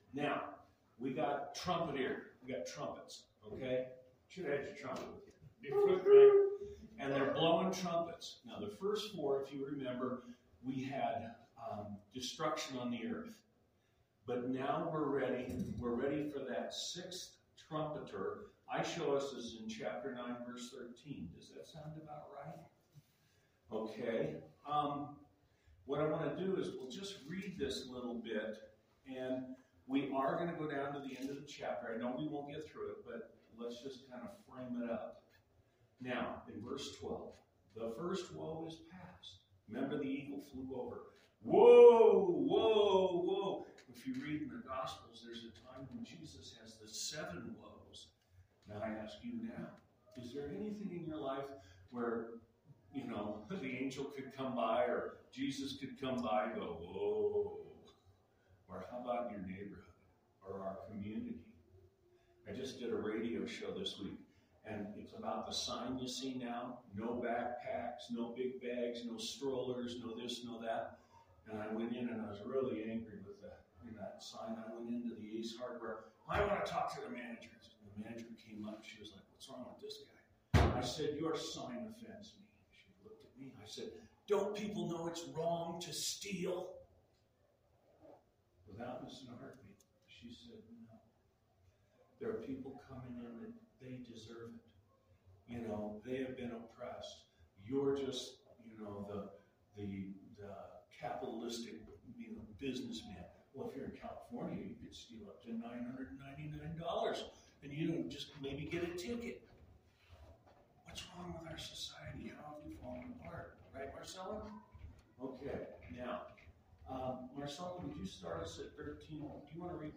Bible Study November 13, 2022 Revelation 9 (Audio only)
Trinity Lutheran Church, Greeley, Colorado Bible Study November 13, 2022 Revelation 9 (Audio only) Nov 14 2022 | 00:26:41 1x 00:00 / 00:26:41 Subscribe Share RSS Feed Share Link Embed